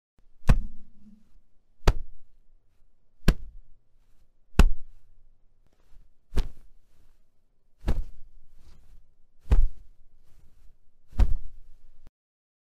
Звуки карате
Скачивайте резкие выдохи (киай), мощные удары руками и ногами, звуки блокировки и движения для монтажа видео, подкастов или использования в мобильных приложениях.
Настоящие звуки ударов каратистов